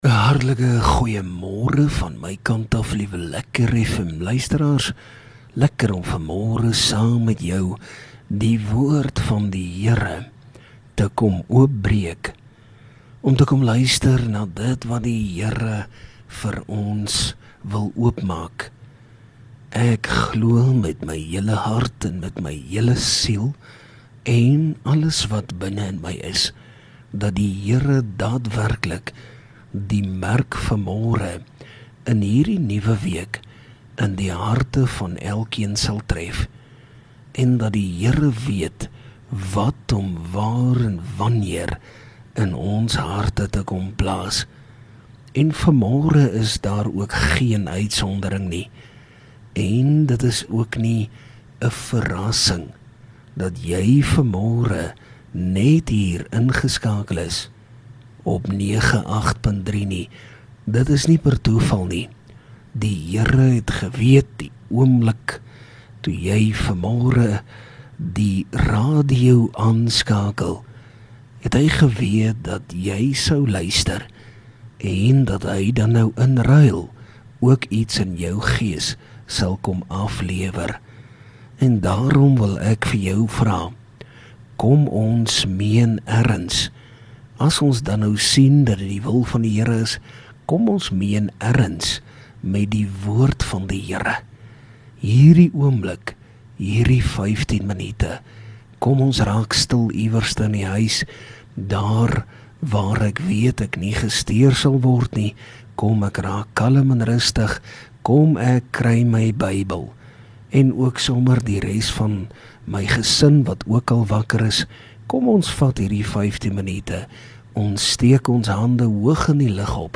LEKKER FM | Oggendoordenkings 1 May Past.